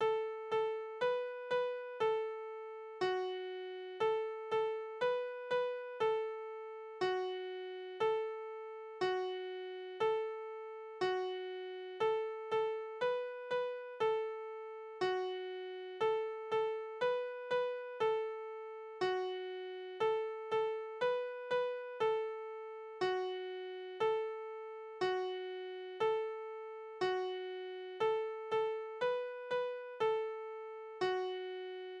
« 11977 » Piep,piep, piep, maok Flöte, Spielverse: Bastlösereime Piep, piep, piep, maok Flöte, piep, piep, piep, maok Flöte, mi ein, di ein, annert Lüer goar kein! Tonart: D-Dur Taktart: 4/4 Tonumfang: Quarte Externe Links